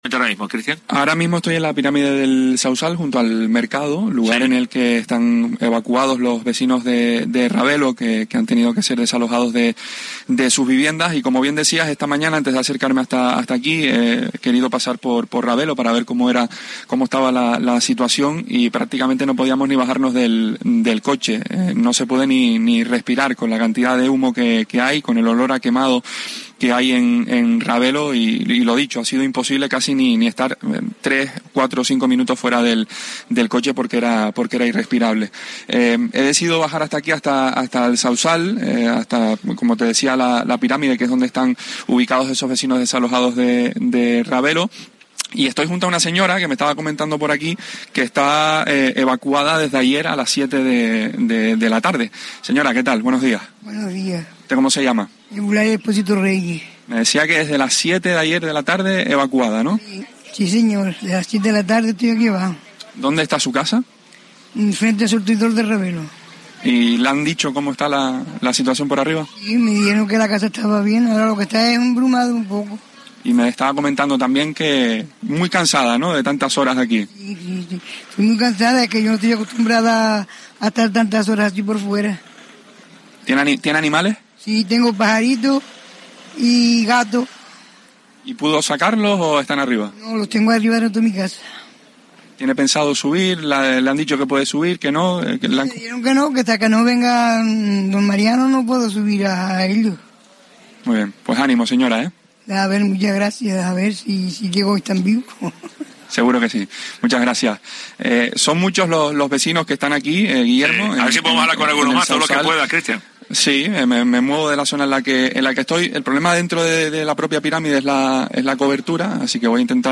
Hoy COPE Canarias ha querido acercarse al municipio de El Sauzal para hablar con los evacuados que se encuentran en el Centro de Usos Múltiples La Pirámide.